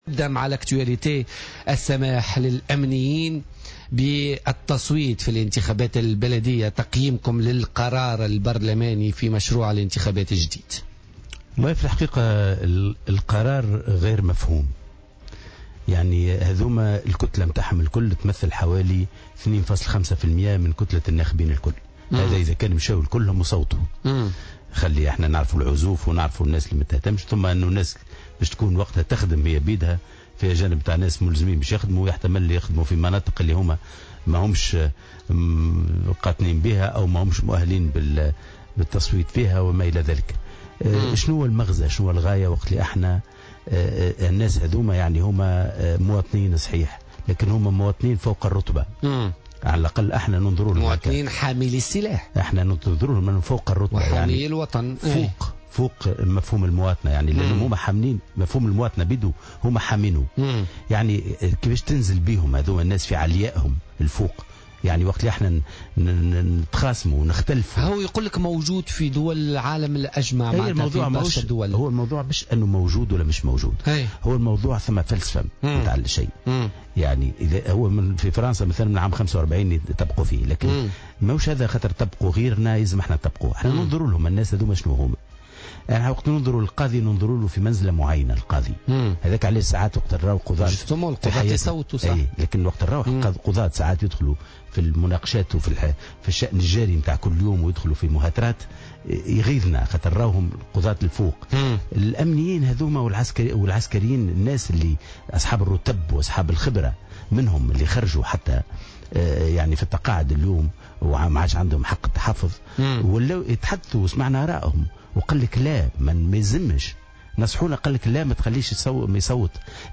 وأضاف في مداخلة له اليوم في برنامج "بوليتيكا" أن الأمنيين والعسكريين هم مواطنون "فوق الرتبة" ولا يمكن إدخالهم في متاهات.